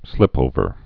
(slĭpōvər)